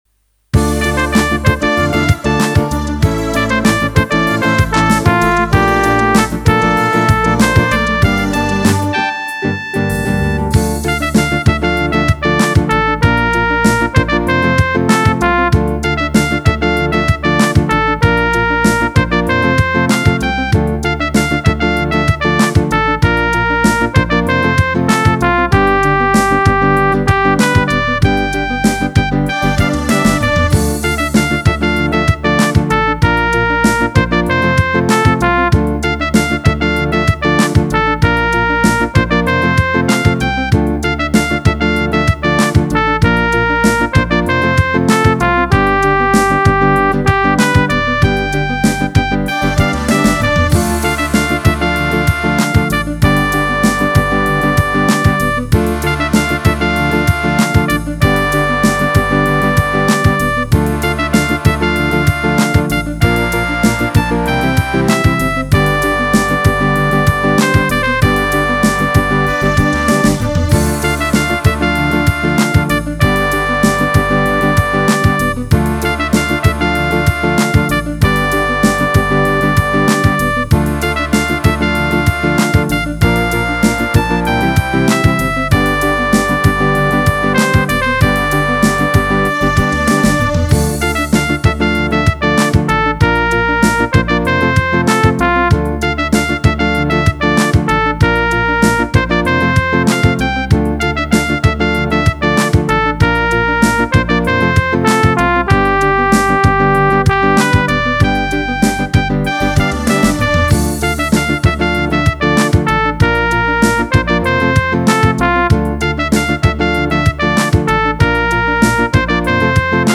Tempo: 96 BPM